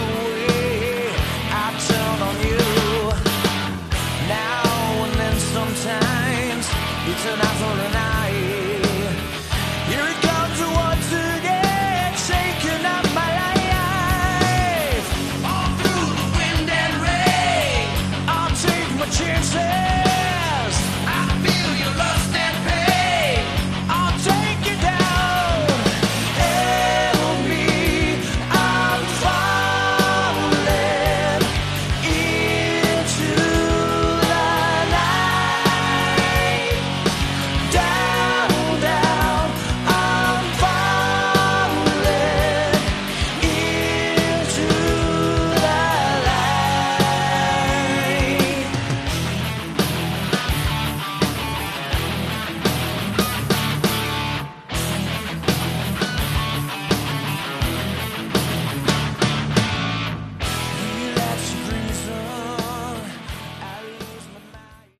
Category: Melodic Metal
guitar, keyboards and vocals